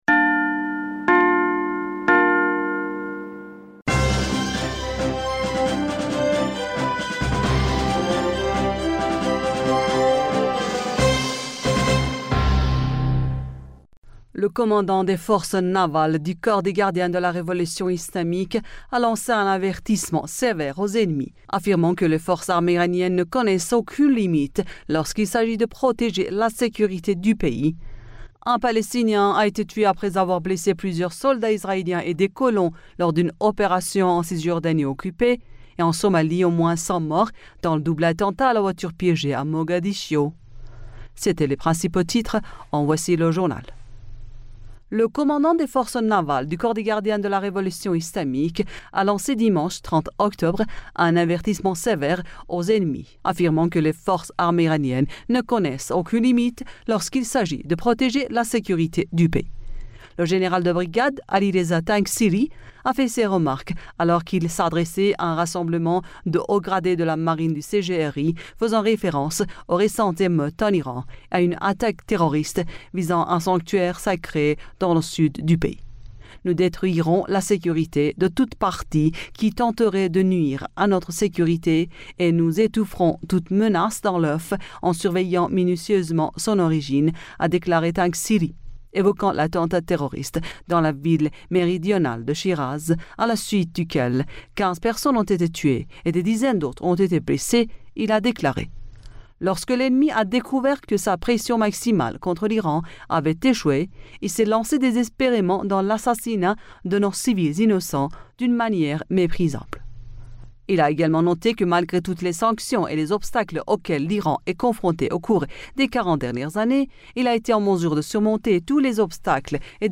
Bulletin d'information Du 31 Octobre